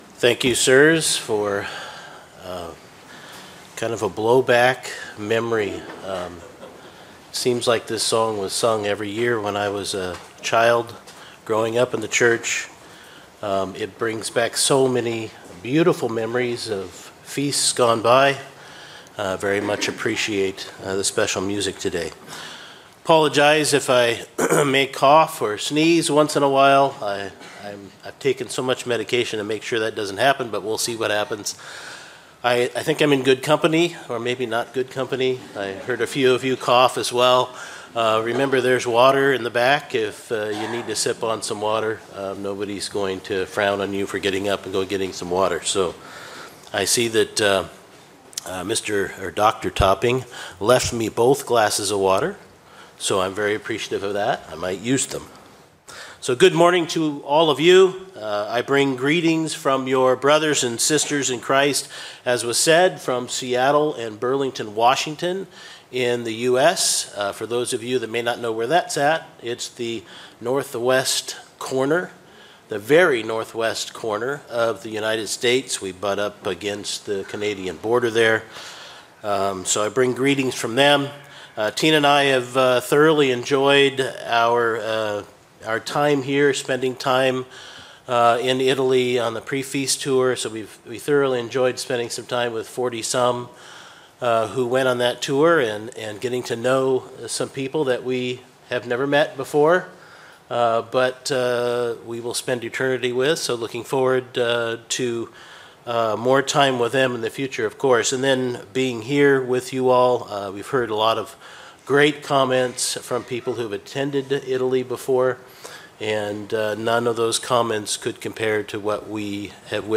Sermons
A Godly Perspective FoT 2025 Sabaudia (Italy): Last Great Day (morning) Given on Oct 14